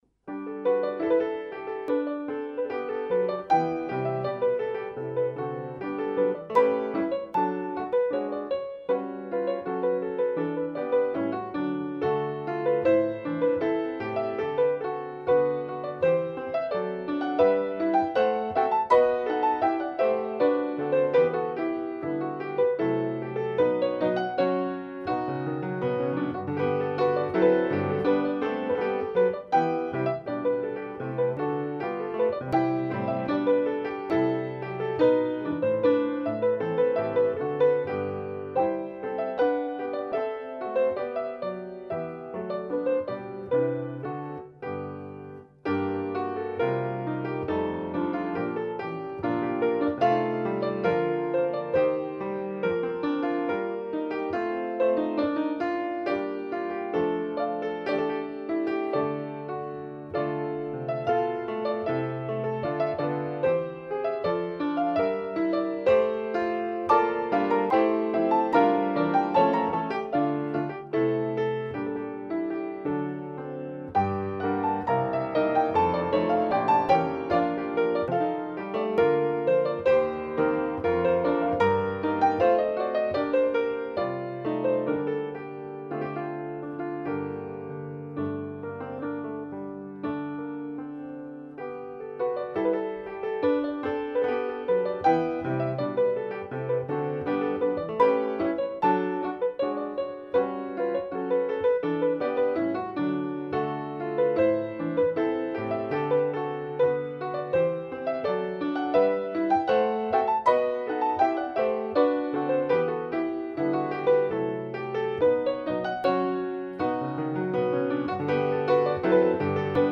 No parts available for this pieces as it is for solo piano.
Piano  (View more Advanced Piano Music)
Classical (View more Classical Piano Music)